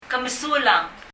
2.　informal な表現　　Ke mesulang　　[kɛ(ə) mɛ(ə)su(:)ləŋ]
発音